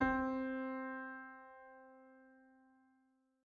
piano-sounds-dev
SoftPiano
c3.mp3